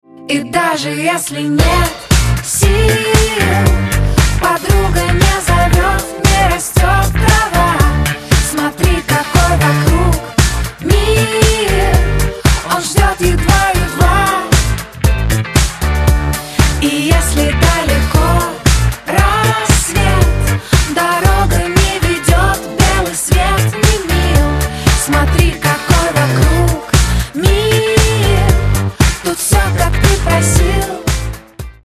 • Качество: 128, Stereo
поп
позитивные
добрые